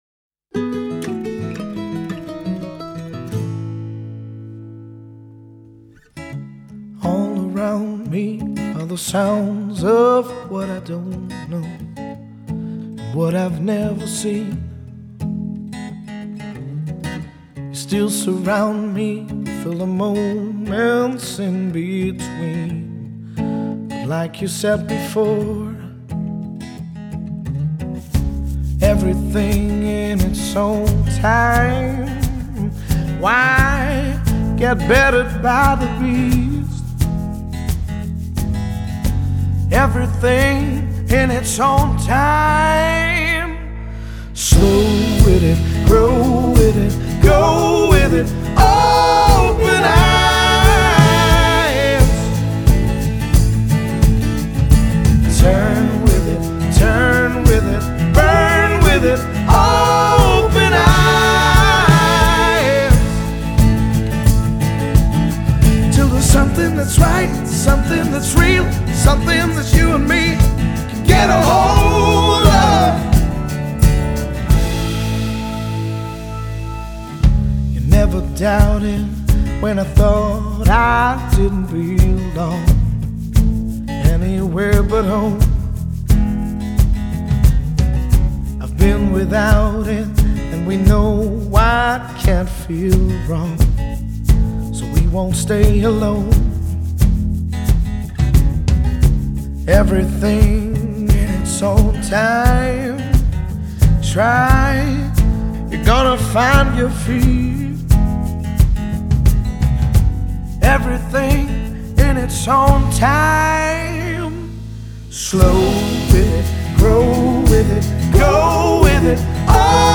Genre: Pop, Rock, Blues